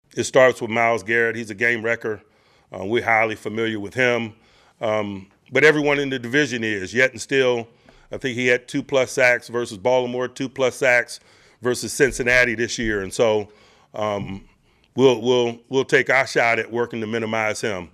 Tomlin held his weekly media event yesterday, and said his sole focus is on the Browns, not on the fact that the Steelers will play two AFC North rivals in five days…Cleveland on Sunday and Cincinnati on Thursday, October 16th.